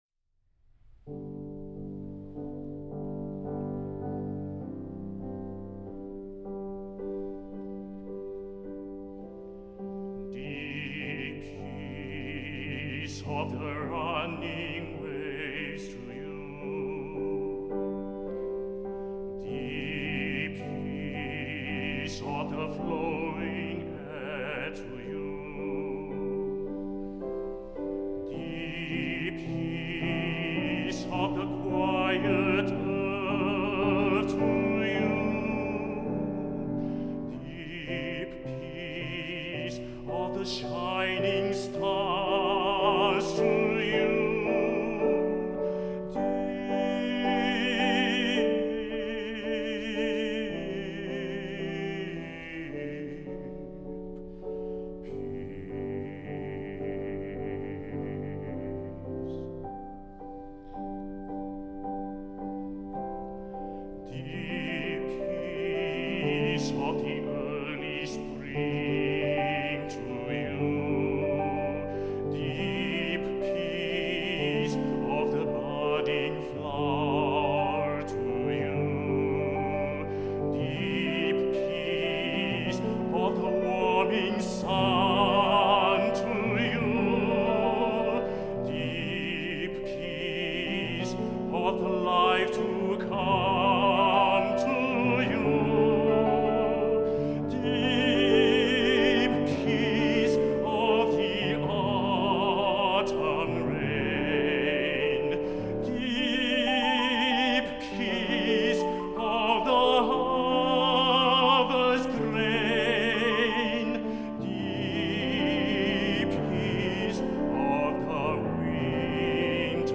for Baritone and Piano (2015)
piano version
baritone